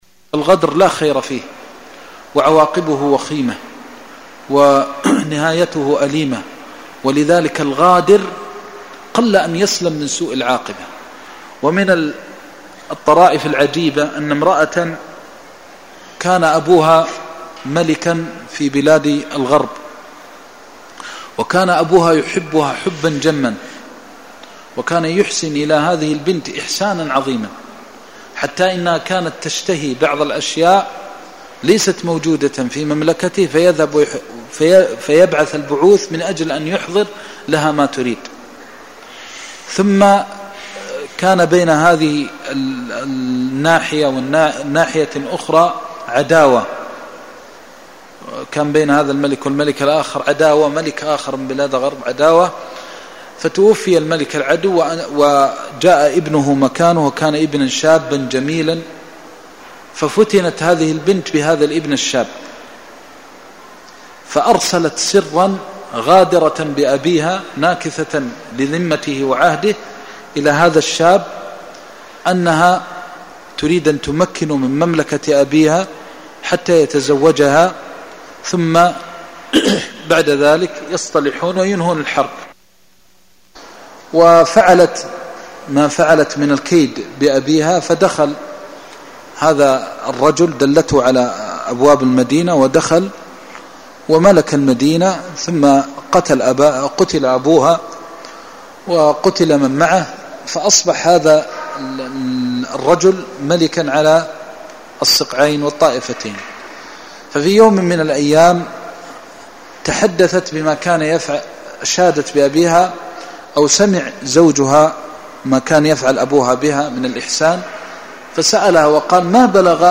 المكان: المسجد النبوي الشيخ: فضيلة الشيخ د. محمد بن محمد المختار فضيلة الشيخ د. محمد بن محمد المختار الجهاد (03)